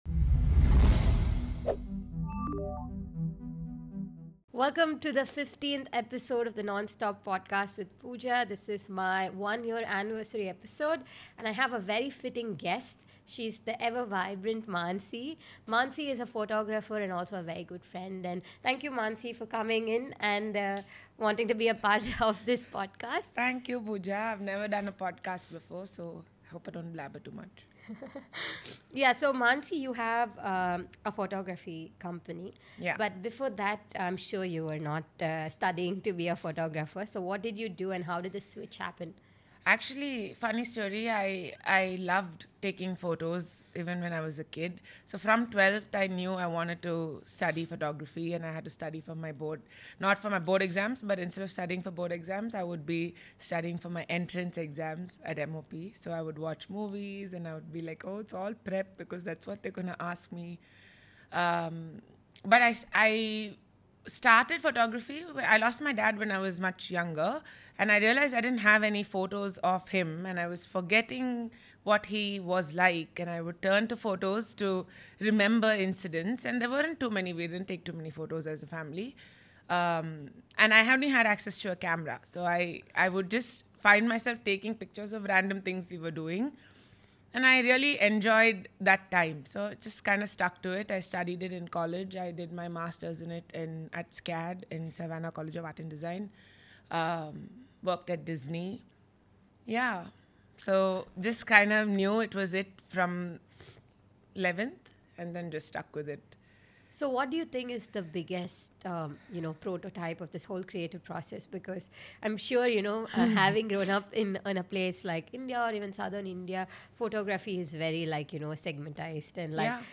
Join us for a conversation about passion, creativity, and the magic of capturing moments that last a lifetime.